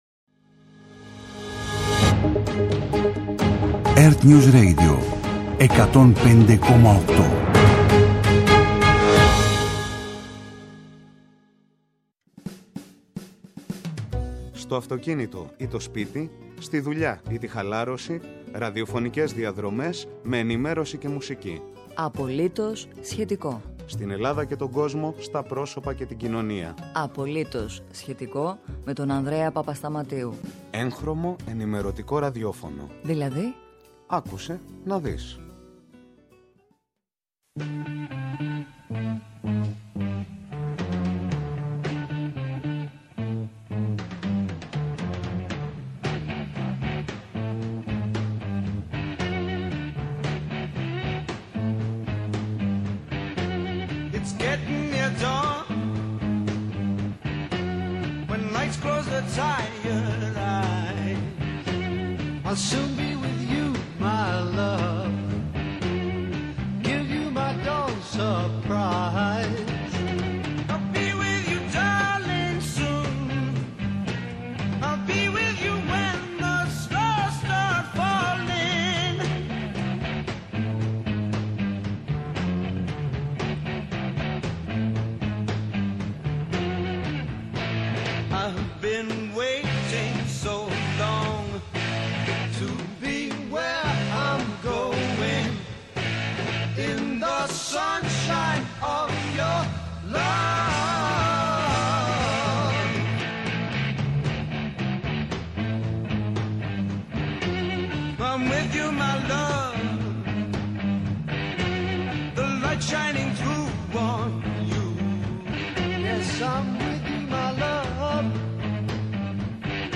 ΕΡΤNEWS RADIO